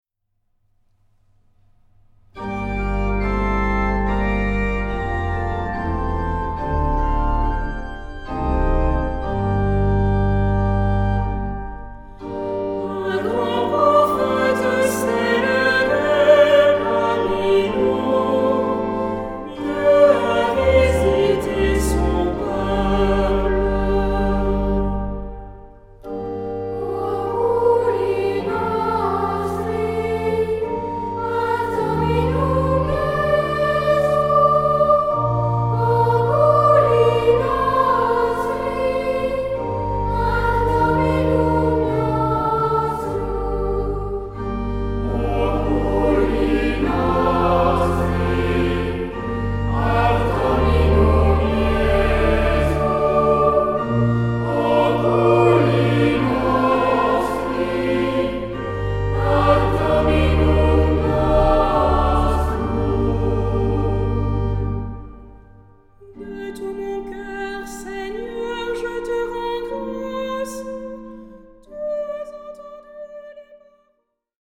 Genre-Style-Forme : Tropaire ; Psalmodie
Caractère de la pièce : recueilli
Type de choeur : SAH OU SATB  (4 voix mixtes )
Instruments : Orgue (1) ; Instrument mélodique (ad lib)
Tonalité : sol mineur